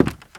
High Quality Footsteps
Wood, Creaky
STEPS Wood, Creaky, Walk 01.wav